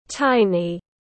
Cực kỳ nhỏ tiếng anh gọi là tiny, phiên âm tiếng anh đọc là /ˈtaɪni/ .
Tiny /ˈtaɪni/